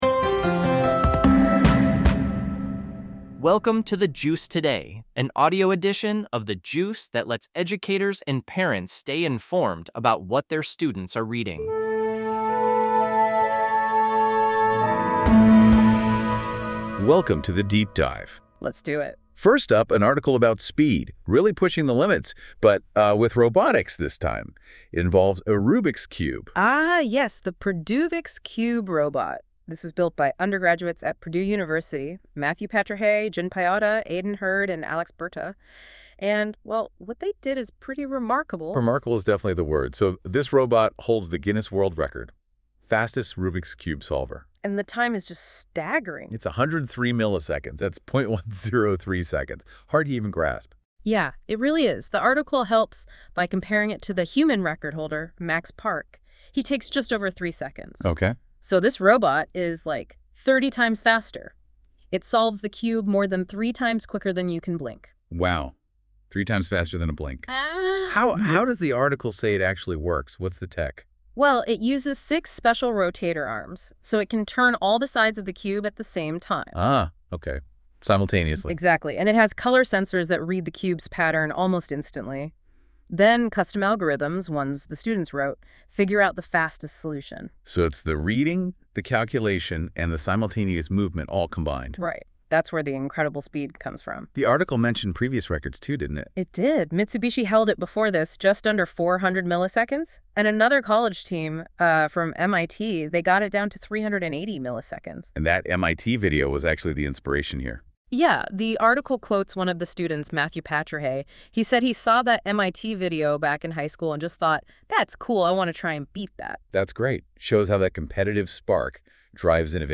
In today's edition:Robot Solver Clever Hawk Waste Upcycling Celtic Rainforest Travel BansVisit Us OnlineThe Juice Learning (Website)Production NotesThis podcast is produced by AI based on the content of a specific episode of The Juice.